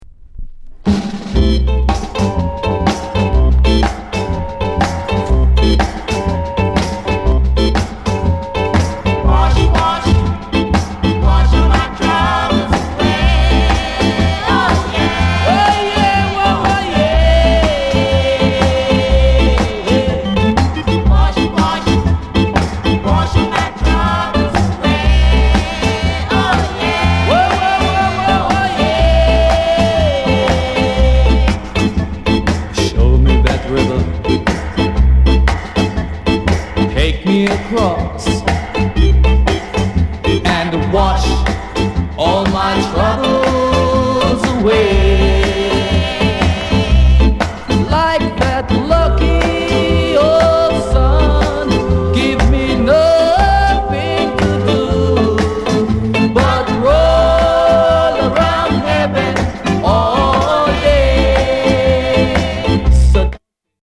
SOUND CONDITION A SIDE EX
KILLER INST